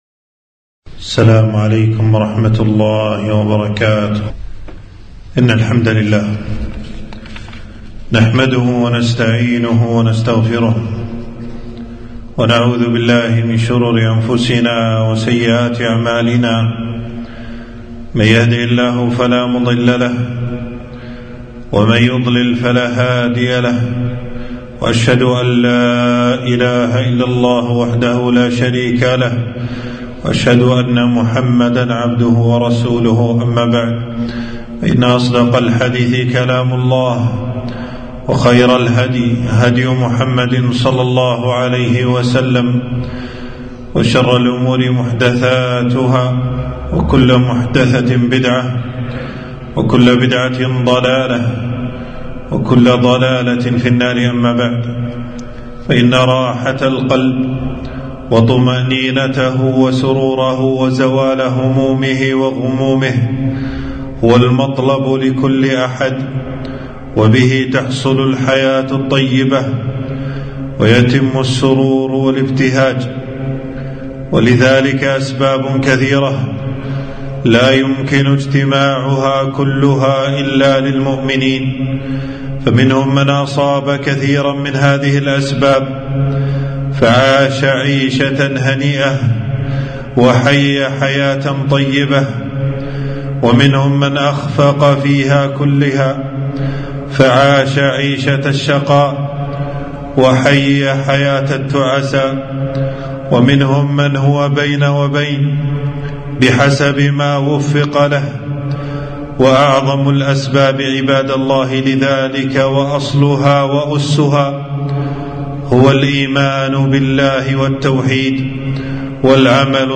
خطبة - الوسائل المفيدة للحياة السعيدة